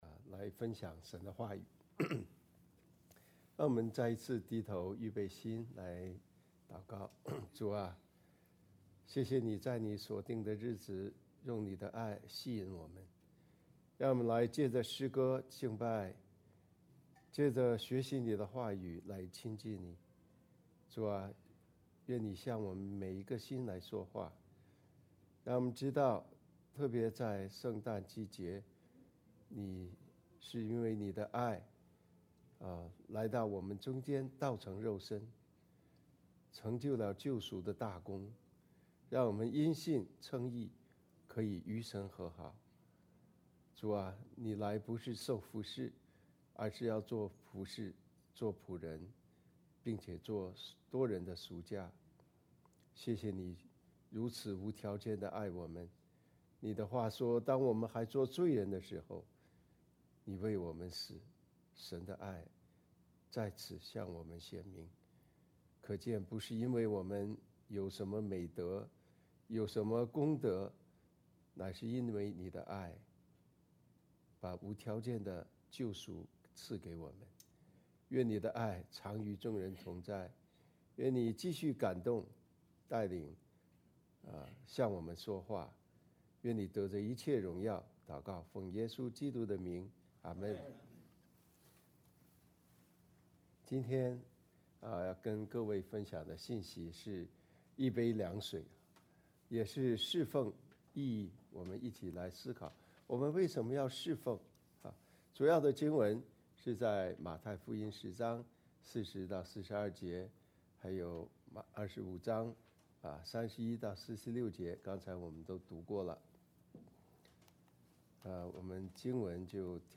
主日证道